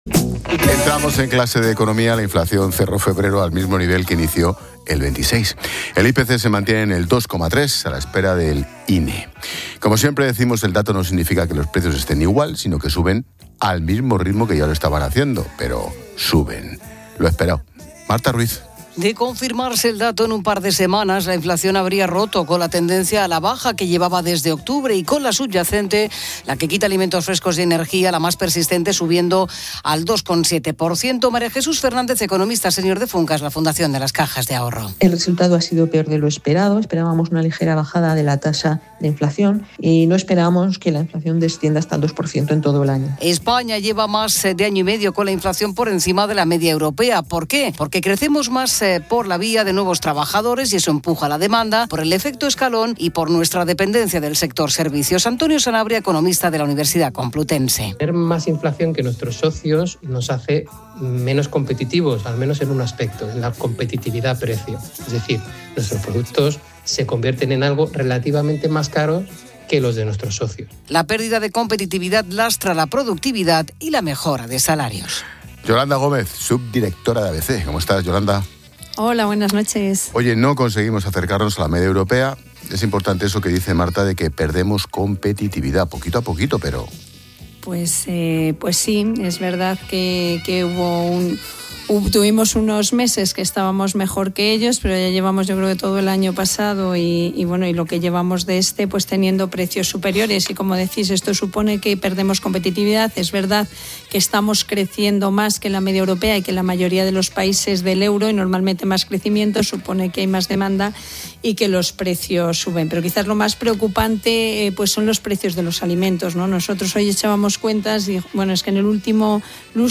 Economía